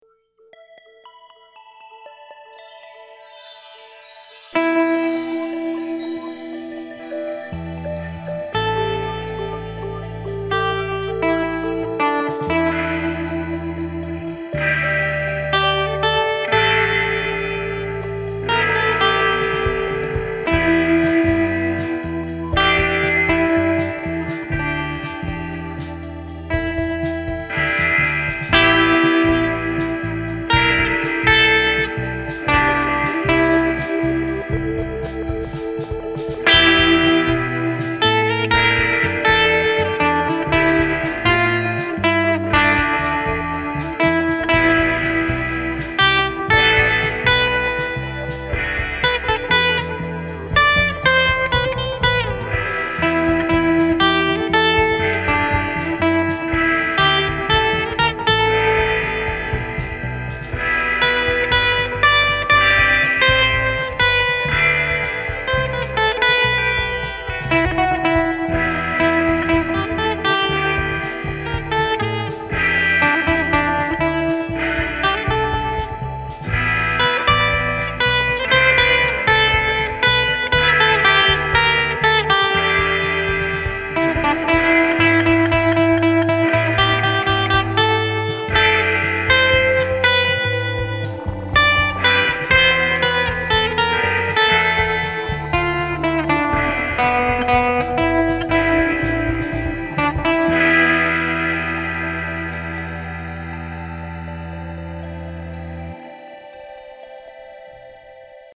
guitar noodling